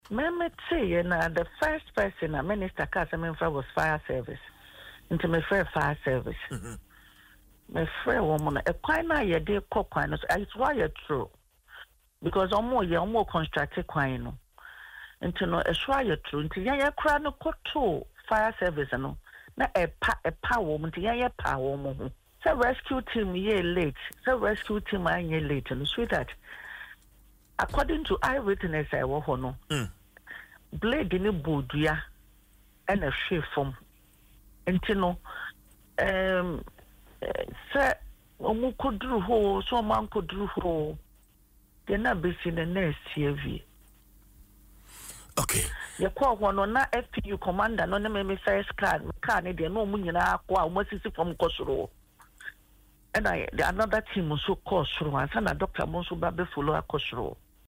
Speaking on Adom FM’s morning show Dwaso Nsem, Ms. Appiah Kannin said her first call after hearing about the crash was to the Ghana National Fire Service.